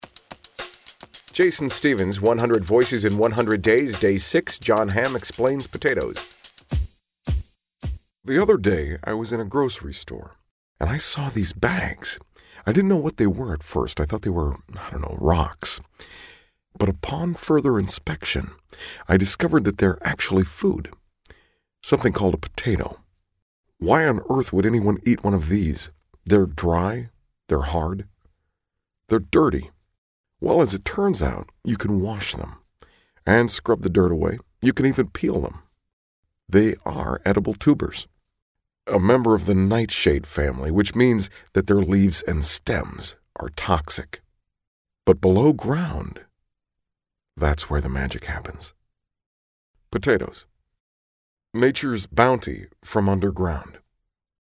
We know Jon Hamm best from Mad Men, in which he played philandering ad executive Don Draper, so that’s the route I went with my Jon Hamm impression.
Tags: Jon Hamm voice match